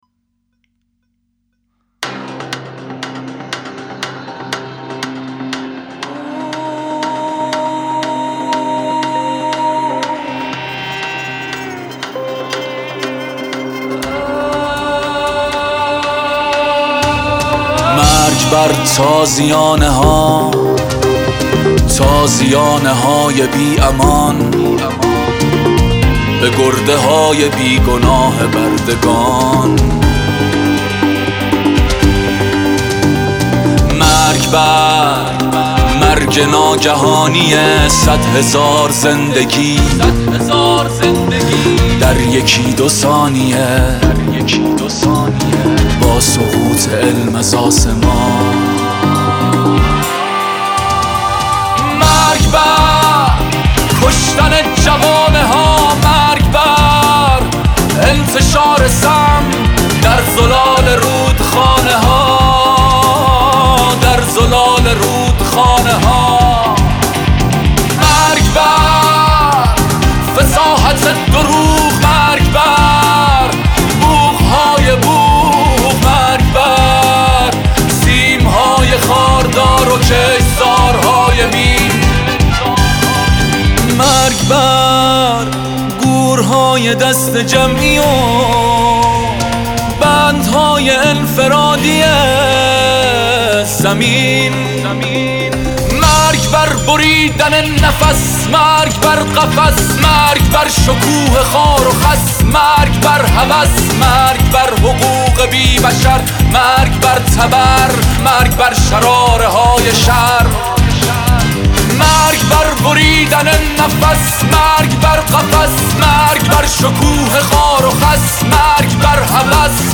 با کیفیت عالی